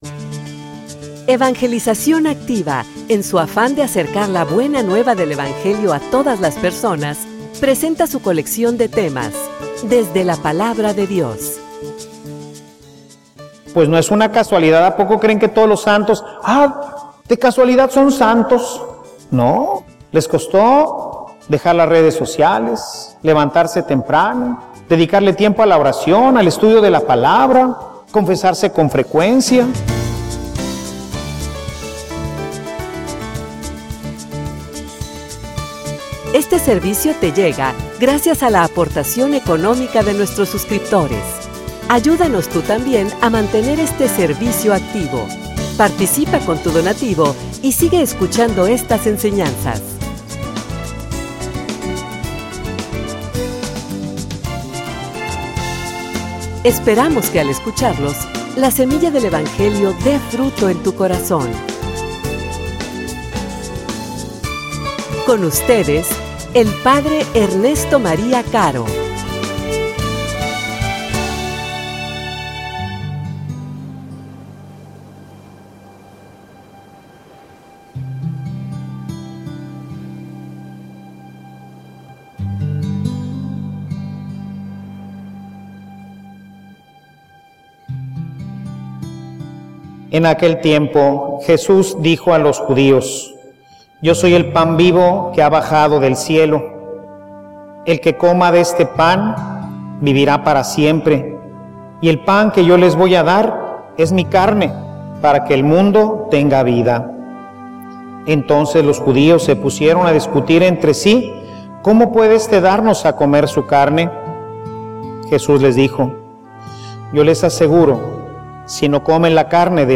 homilia_La_muerte_momento_de_triunfo.mp3